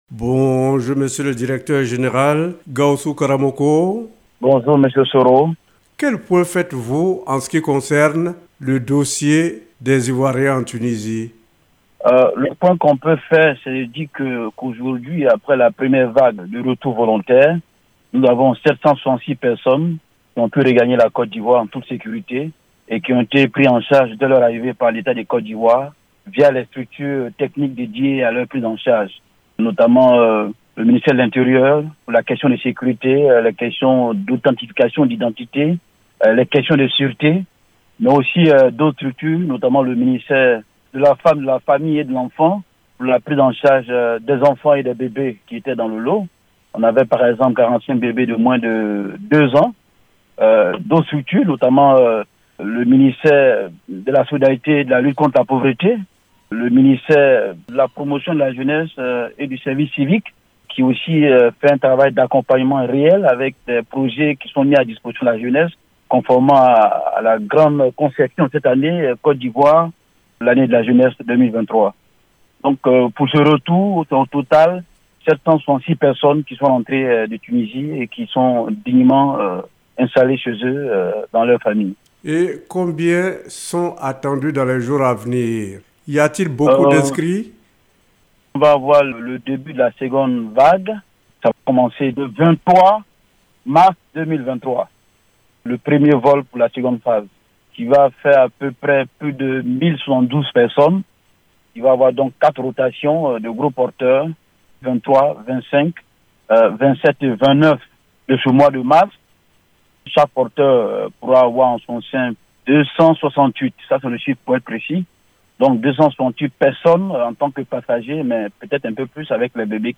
Nous en arrivons à l’Invité de la Rédaction qui est Docteur Gaoussou Karamoko.